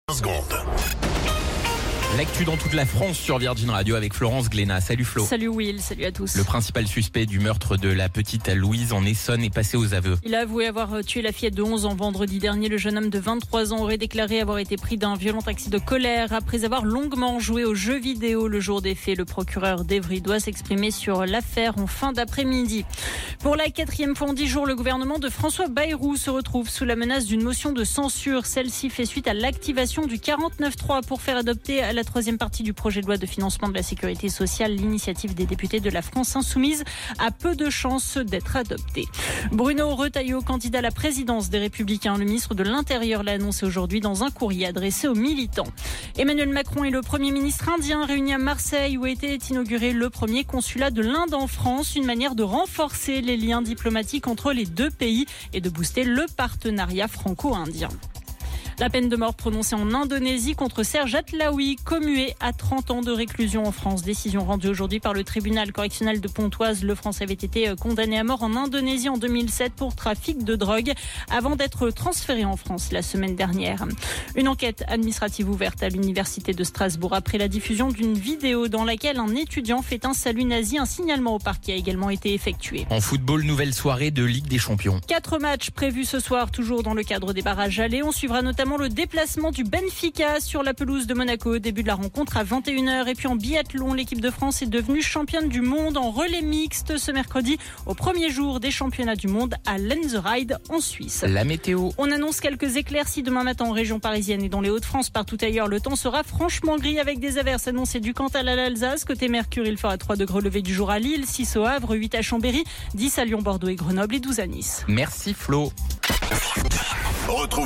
Flash Info National 12 Février 2025 Du 12/02/2025 à 17h10 .